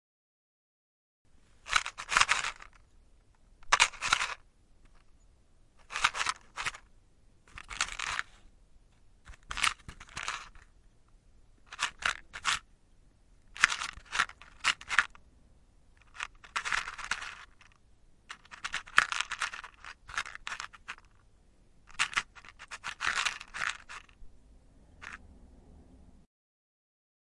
一盒火柴
描述：放大H6 / 48kHz24Bit记录火柴盒。
Tag: 匹配 蜡烛 罢工 烟雾 火焰 火柴盒 香烟 燃烧 OWI